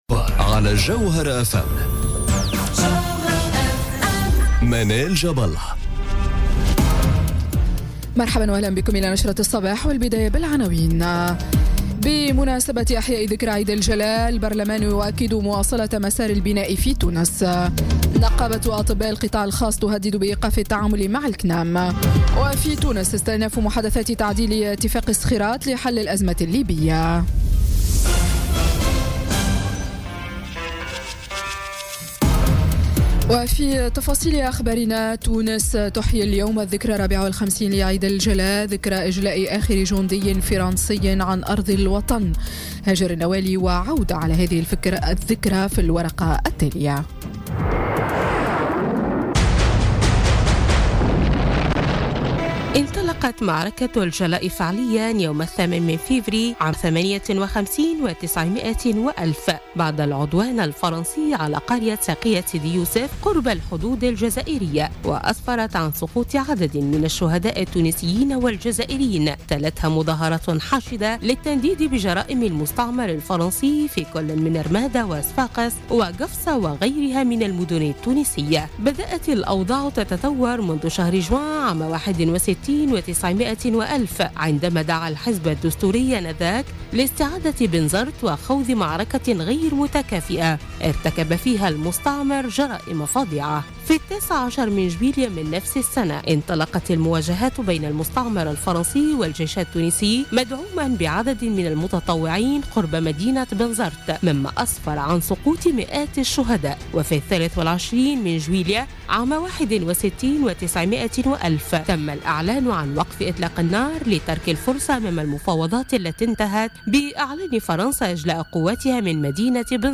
نشرة أخبار السابعة صباحا ليوم الأحد 15 أكتوبر 2017